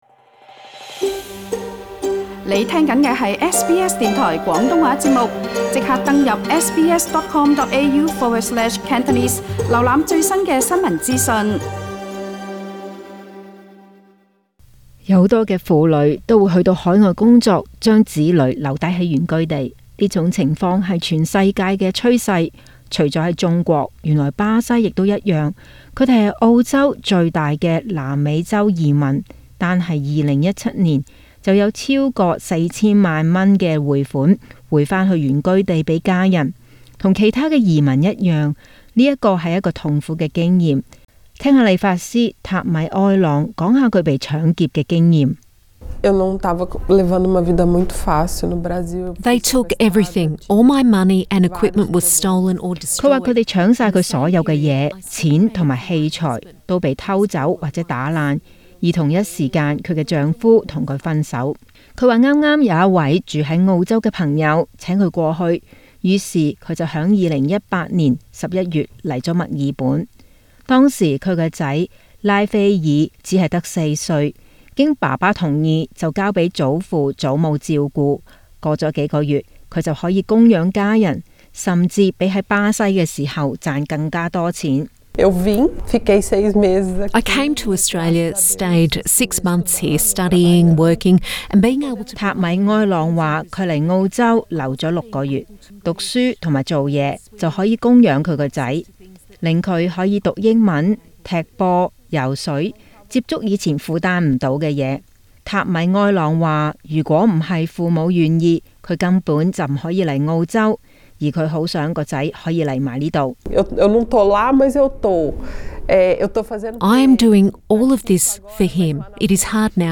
SBS廣東話節目